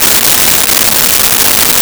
Vacuum Cleaner 02
Vacuum Cleaner 02.wav